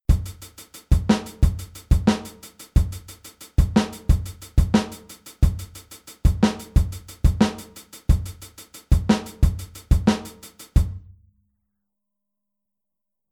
Aufteilung linke und rechte Hand auf HiHat und Snare
Groove10-16tel.mp3